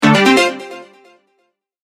ボタン・システム （87件）
出題1.mp3